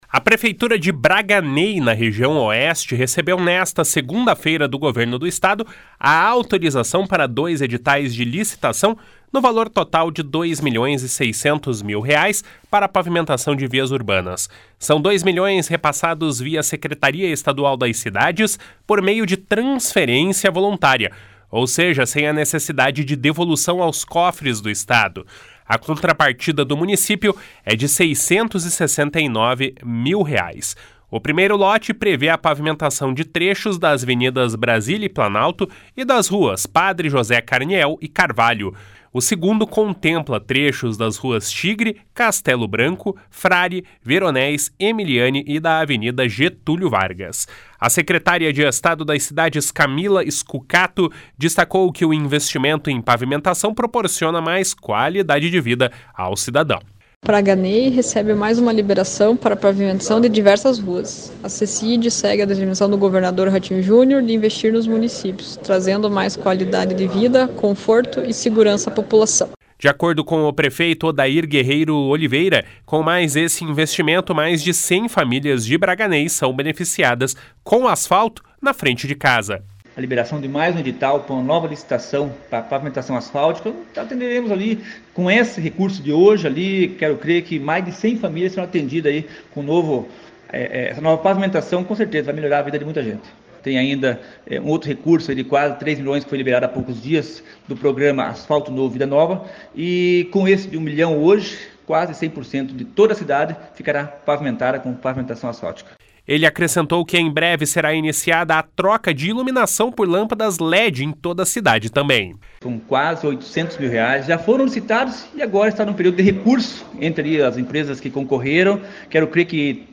A secretária de Estado das Cidades, Camila Scucato, destacou que o investimento em pavimentação proporciona mais qualidade de vida ao cidadão. // SONORA CAMILA SCUCATO //
De acordo com o prefeito Odair Guerreiro Oliveira, com mais esse investimento, mais de 100 famílias de Braganey serão beneficiadas com o asfalto na frente de casa. // SONORA ODAIR GUERREIRO OLIVEIRA //